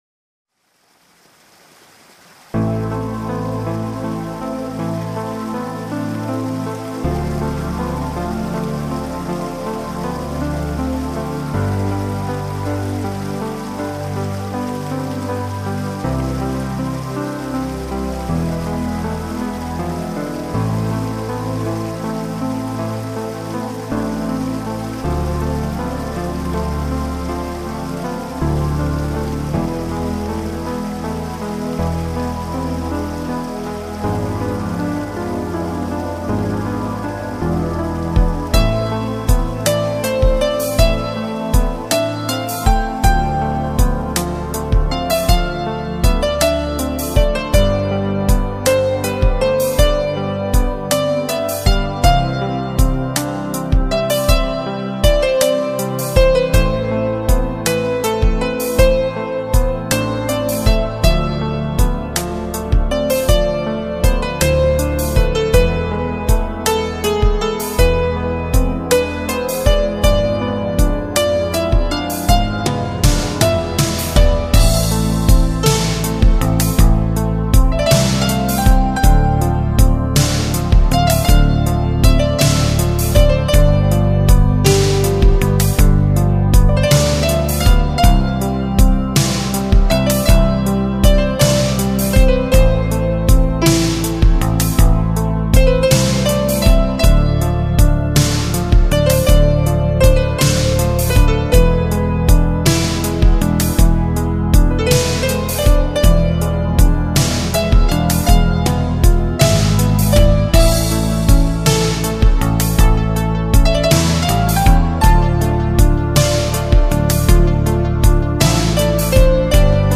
Инструментальная_музыка_—_Вальс_Дождя_КРАСИВАЯ_МУЗЫКА_www_lightaudio_